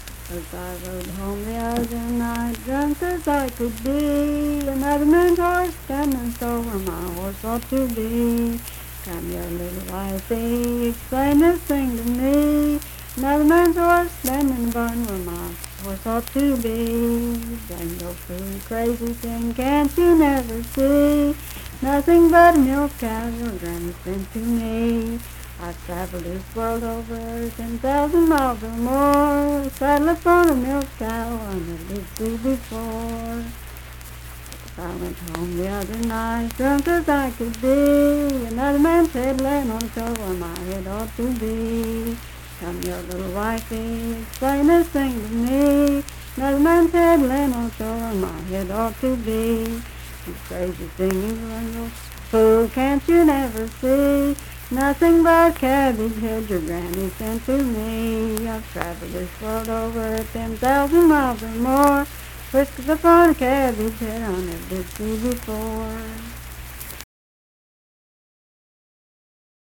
Unaccompanied vocal music
Verse-refrain 8(4w/R).
Voice (sung)
Sutton (W. Va.), Braxton County (W. Va.)